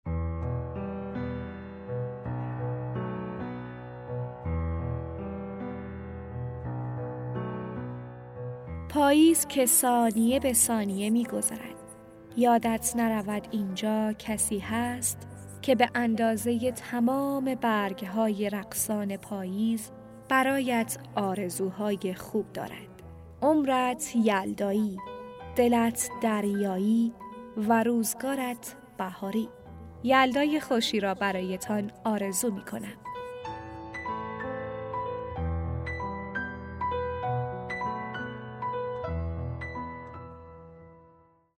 پیام تبریک شب یلدا عاشقانه صوتی